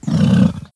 c_goril_hit2.wav